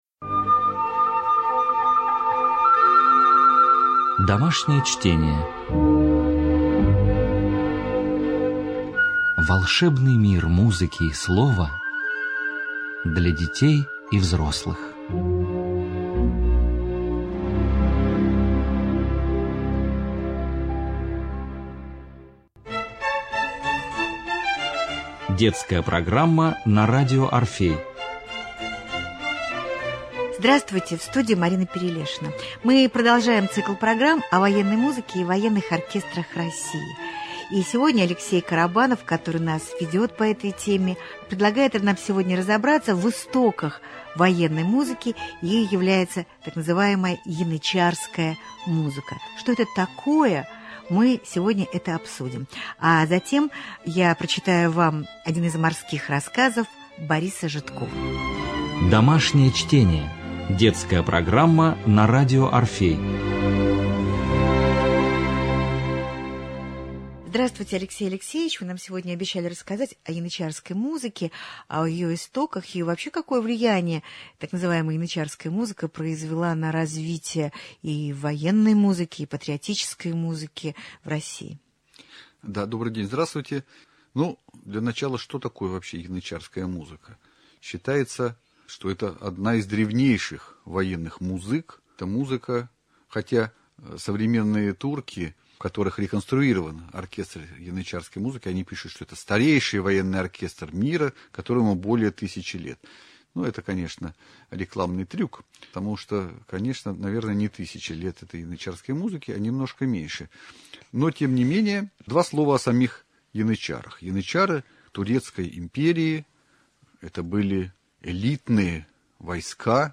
Цикл бесед о патриотической и военной музыке